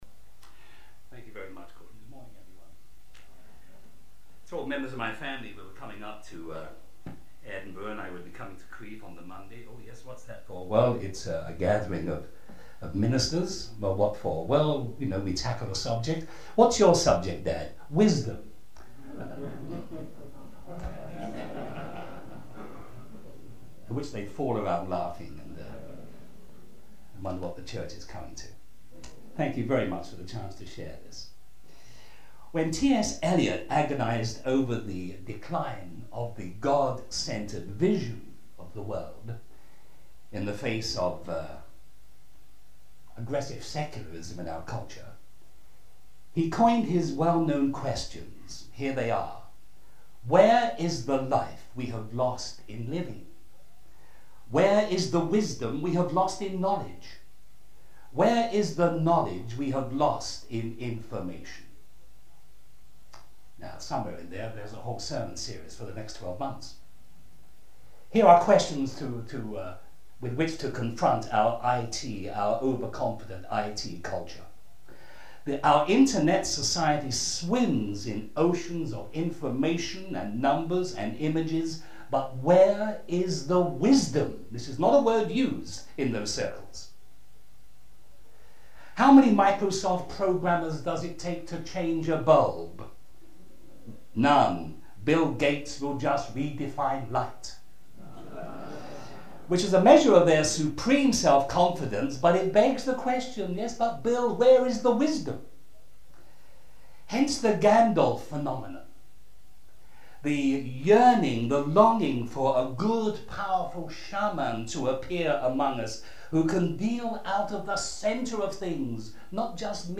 For an expanded account of his remarkable ministry click here Listen to audio Some Crieff Fellowship talks are now available for free download.